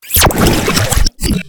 Cri de Polthégeist dans Pokémon HOME.